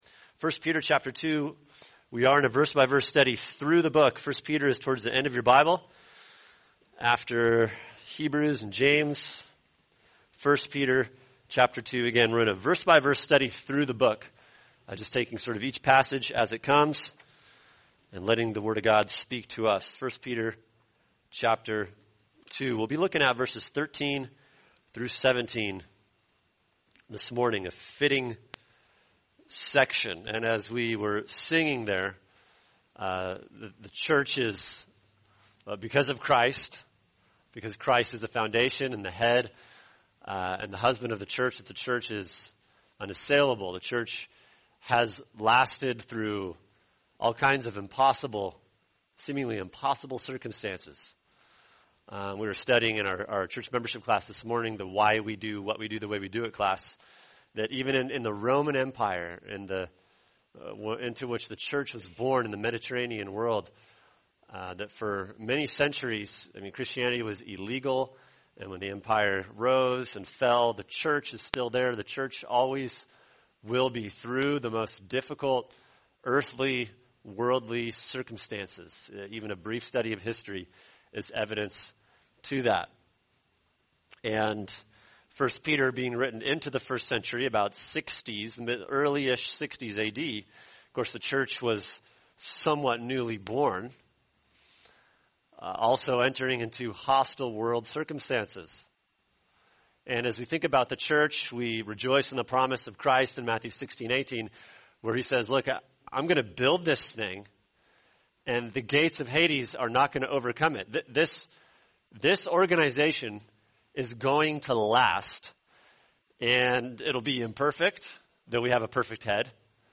[sermon] 1 Peter 2:13-17 The Christian & Government | Cornerstone Church - Jackson Hole